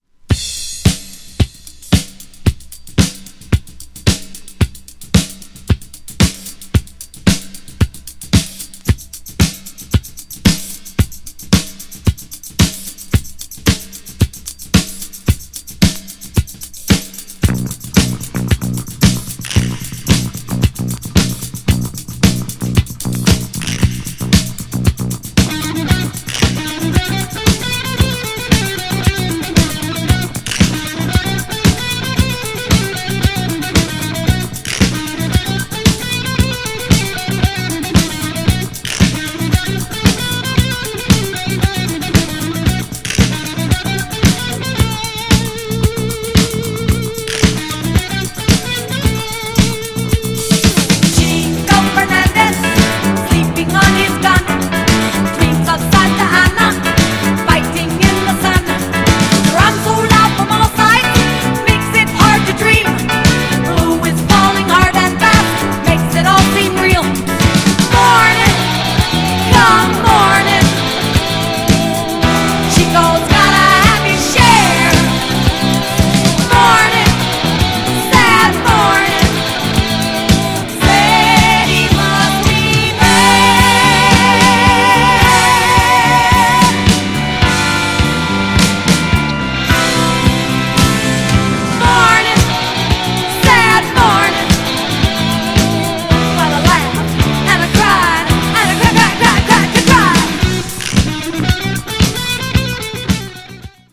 GENRE Dance Classic
BPM 121〜125BPM
じわじわ盛り上がる